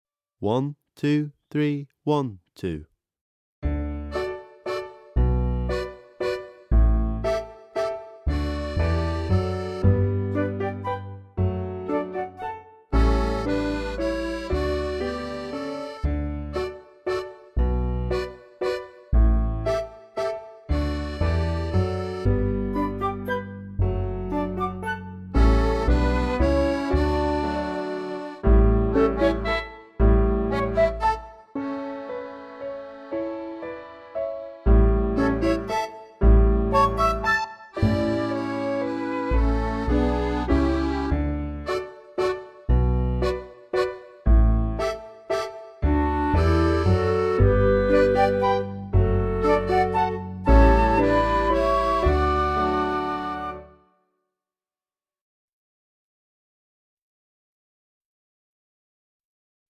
VS The Collier's Waltz (backing track)